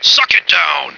flak_m/sounds/male1/int/M1suckitdown.ogg at ac4c53b3efc011c6eda803d9c1f26cd622afffce